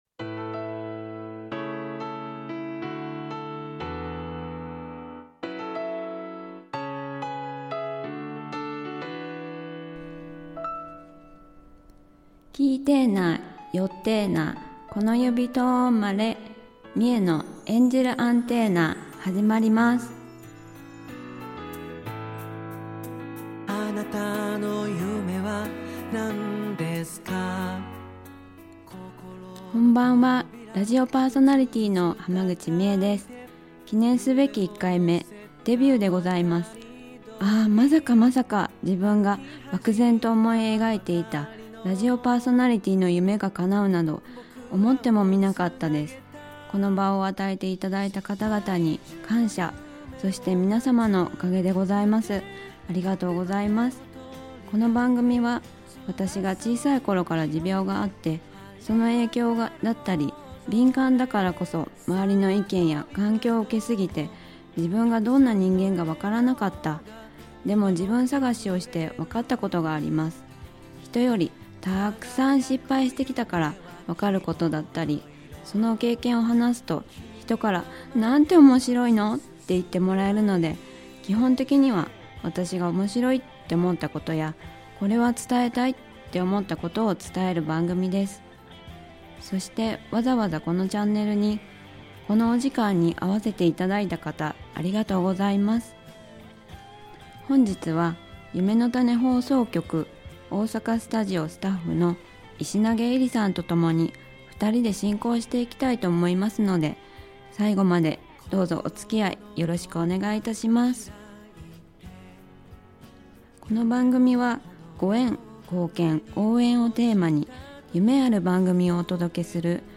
大阪スタジオ